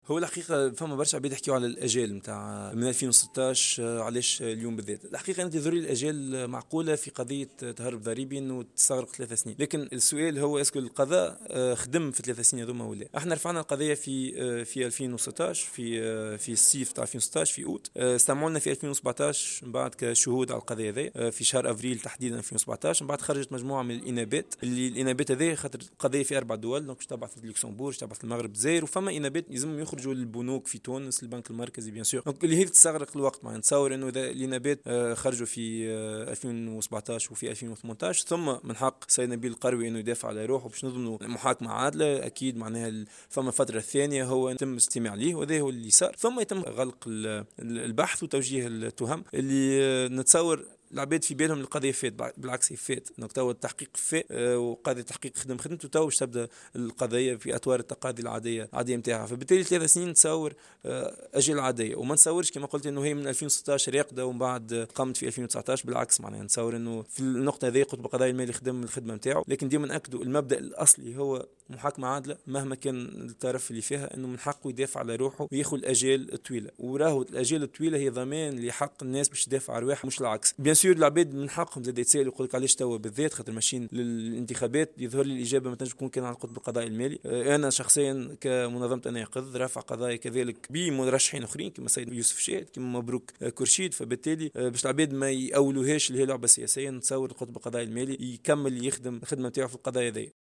خلال ندوة صحفية عقدتها المنظمة بمناسبة إطلاق موقع "بالكمشة"